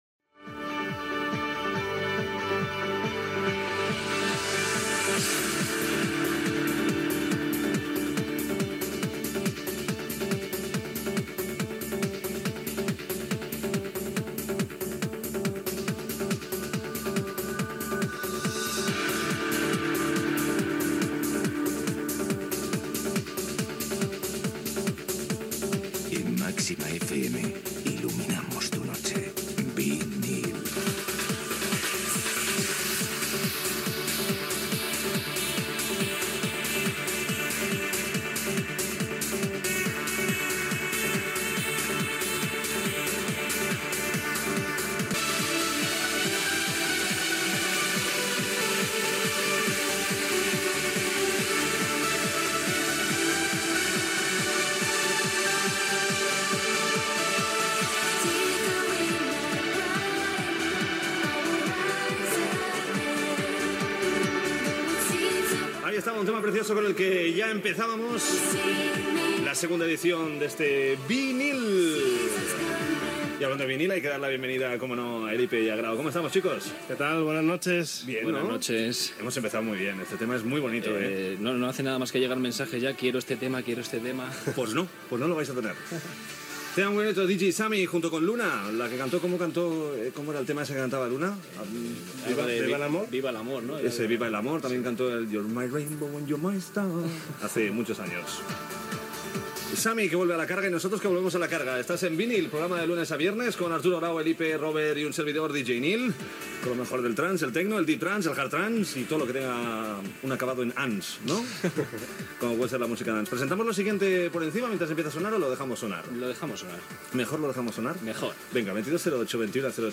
Identificació del programa, tema musical, comentaris, hora, indentificació, tema musical, concurs, tema musical
Musical